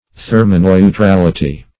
Search Result for " thermoneutrality" : The Collaborative International Dictionary of English v.0.48: Thermoneutrality \Ther`mo*neu*tral"i*ty\, n. (Chem.) Neutrality as regards heat effects.